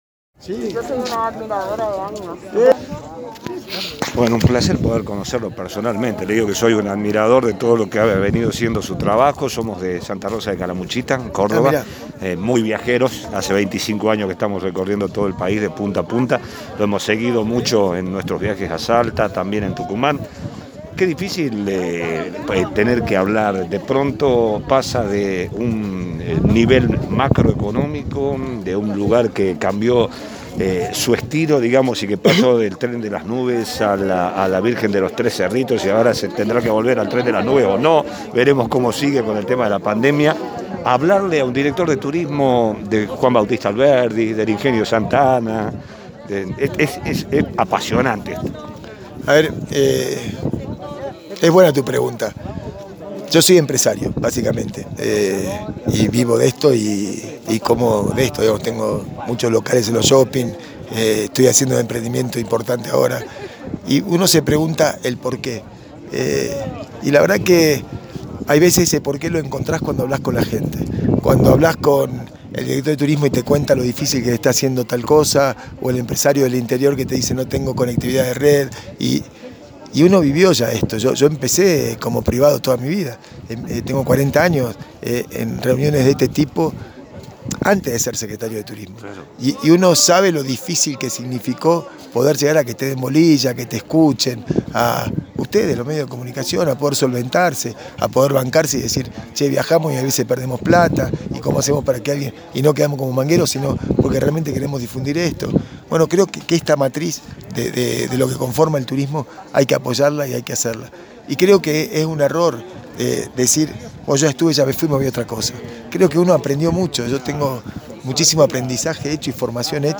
Flash Fm presente en el 19º Encuentro de Municipios Turísticos en Tafi Del Valle, en este caso dialogamos con Bernardo Racedo Aragón ex secretario de turismo de Salta y Tucumán.